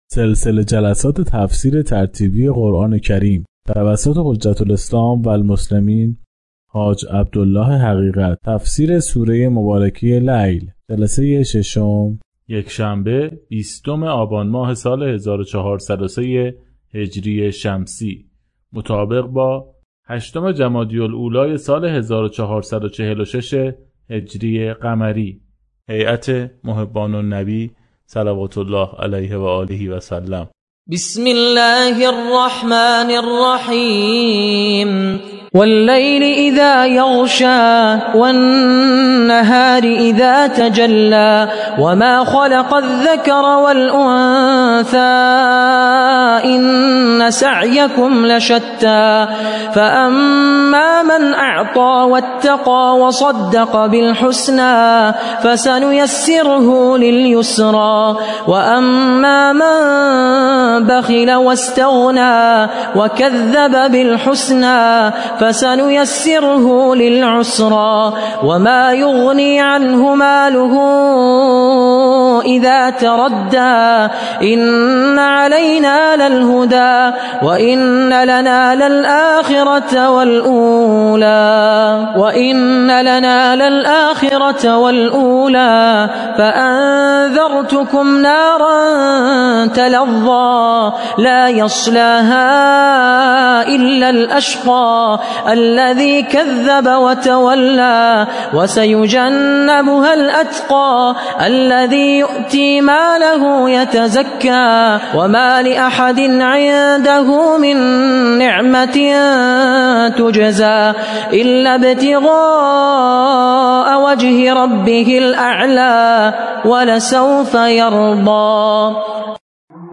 سلسله جلسات تفسیر قرآن کریم